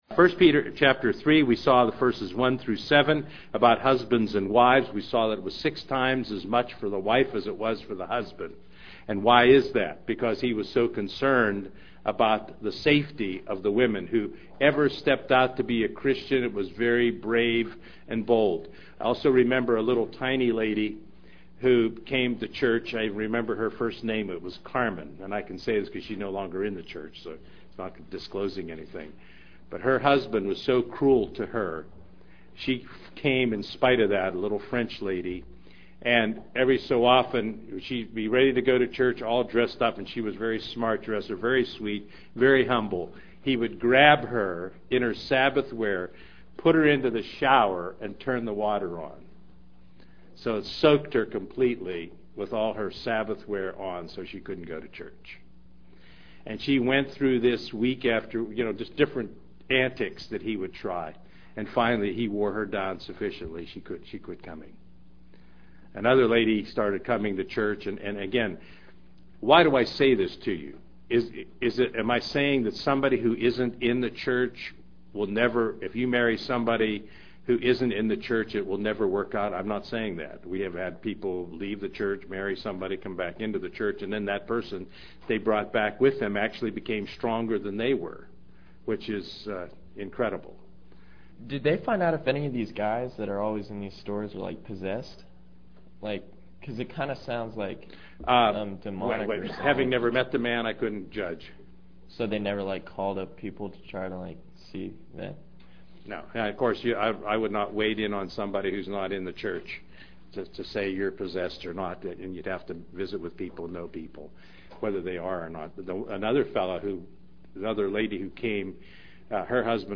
The General Epistles classes